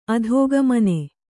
♪ adhōgamane